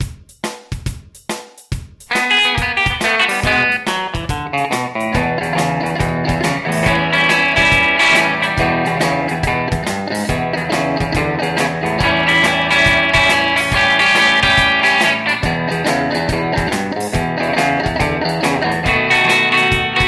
Piste guitare (midi) tablature midi